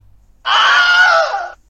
Wilhelm Scream